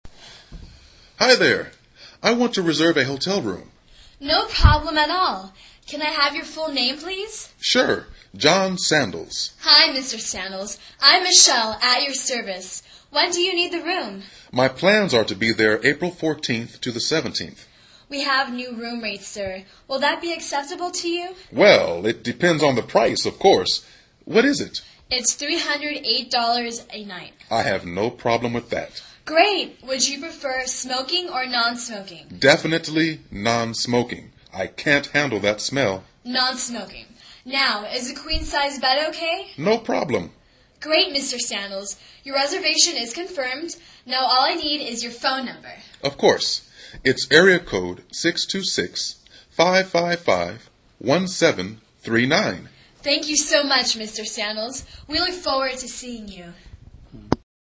英语对话之旅馆预订-2 听力文件下载—在线英语听力室